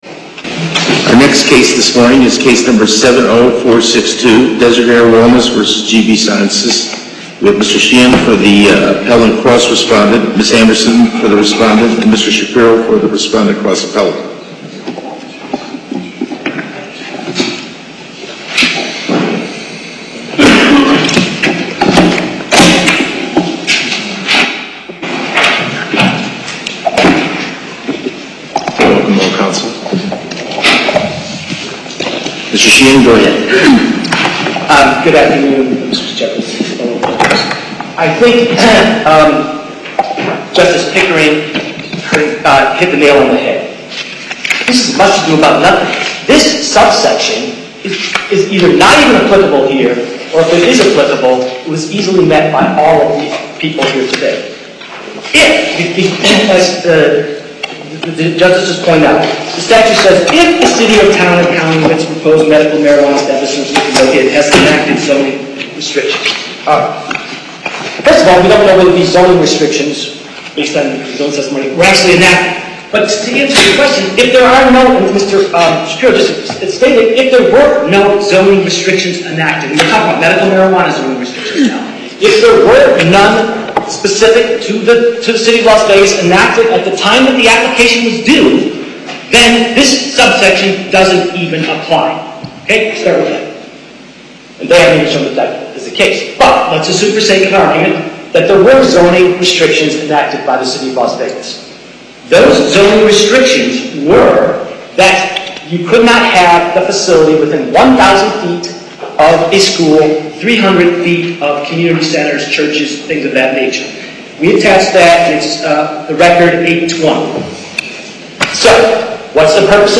Loading the player Download Recording Docket Number(s): 70462 Date: 12/05/2017 Time: 10:30 A.M. Location: Las Vegas Before the En Banc Court, Chief Justice Cherry presiding.